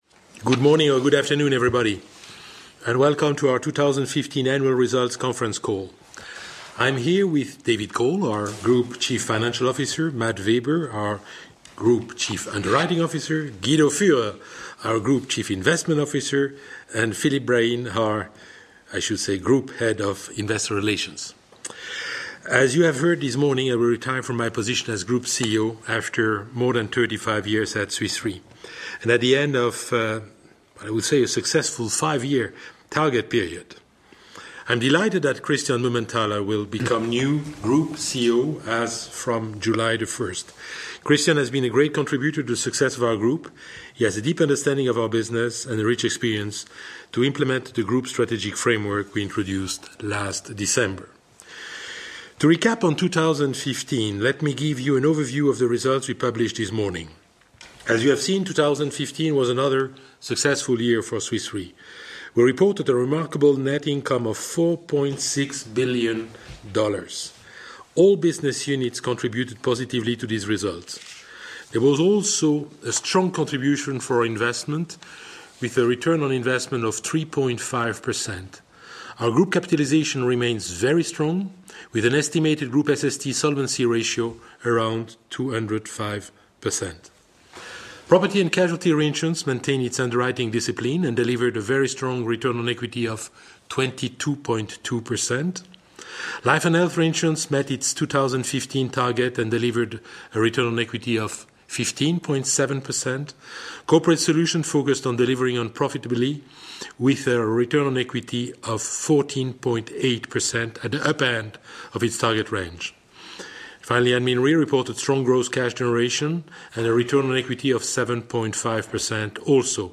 Analysts Conference call recording
2015_fy_qa_audio.mp3